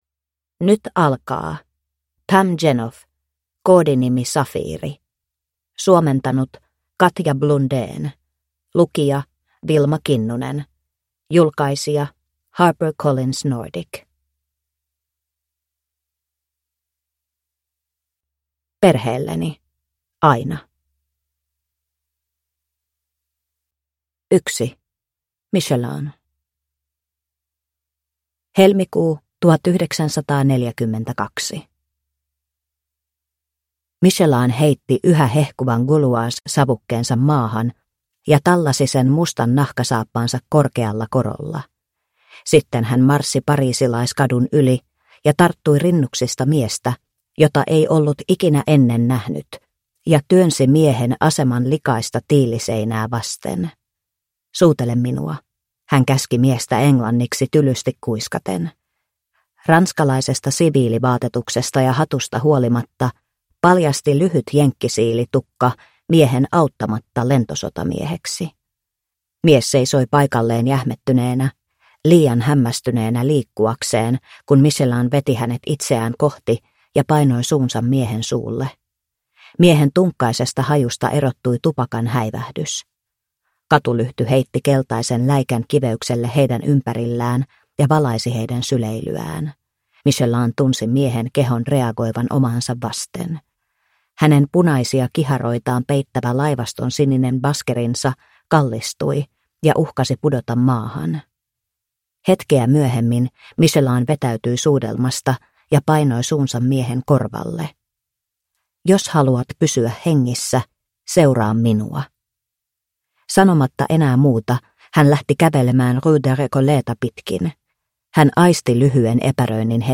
Koodinimi Safiiri – Ljudbok – Laddas ner